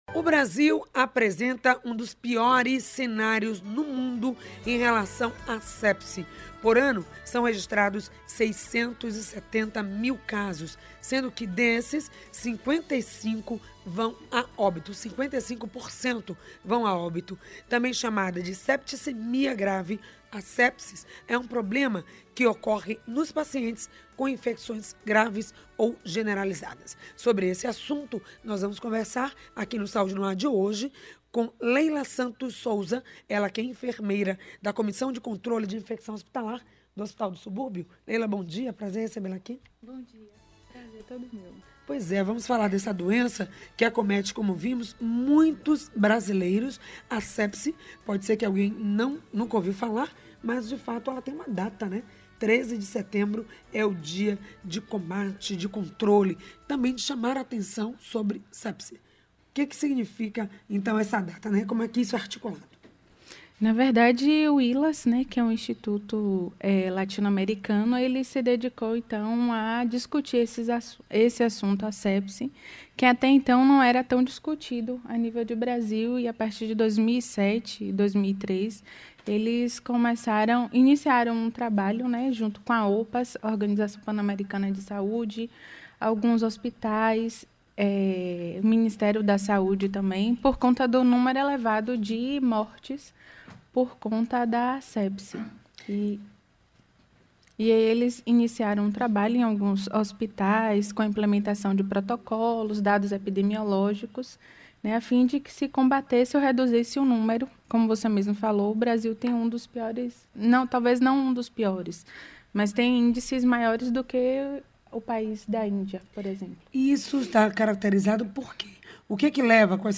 A enfermeira falou sobre sepse. Por ano, são registrados 670 mil casos, sendo que desses 55% vão a óbito.